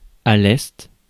Ääntäminen
IPA: /ɛst/